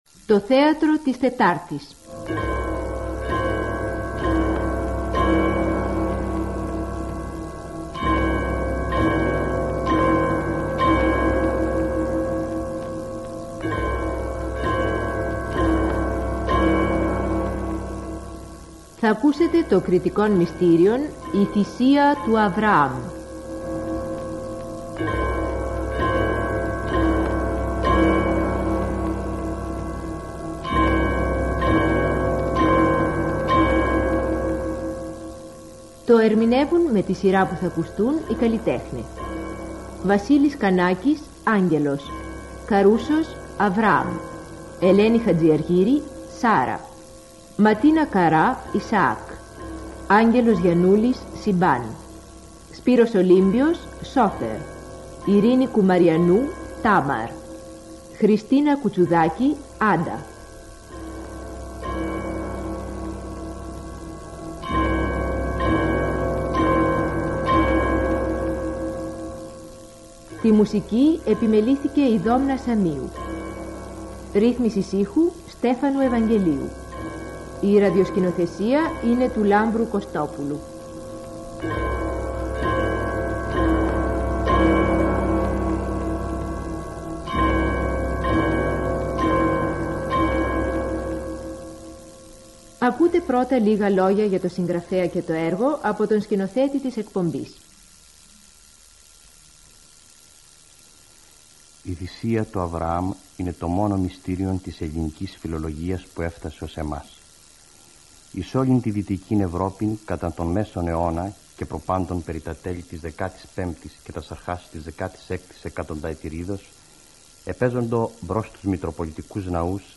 Ακολούθως σας παραθέτουμε το πασίγνωστο θεατρικό έργο – “θρησκευτικό δράμα” του 17-ου αιώνος, “η Θυσία του Αβραάμ”, του Κρητικού ποιητού, Βιτσέντζου Κορνάρου, όπως αυτό μεταδόθηκε από την Κρατική ραδιοφωνία, – ΕΡΤ.